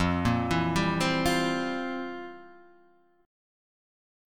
F Major 7th Suspended 2nd Suspended 4th